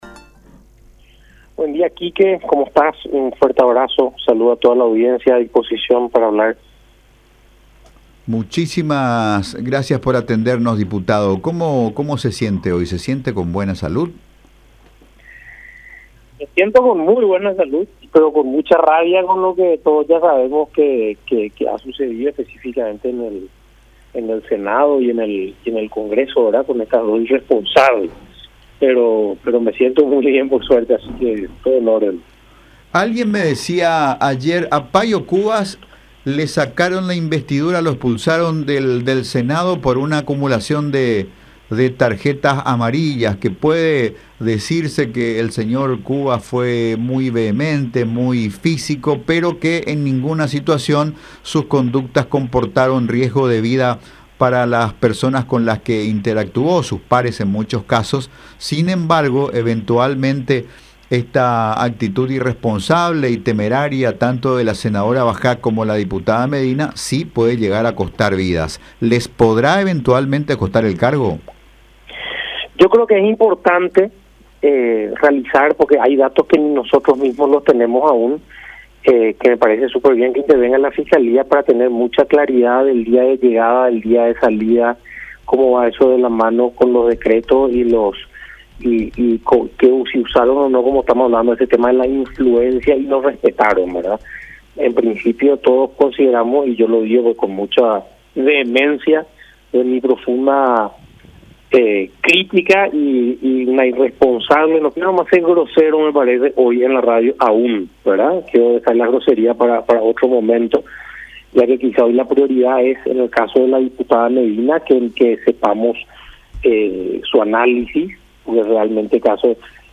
“Me siento con muy buena salud, pero con mucho rabia por lo sucedido con estas dos irresponsables”, expresó Villarejo en diálogo con La Unión.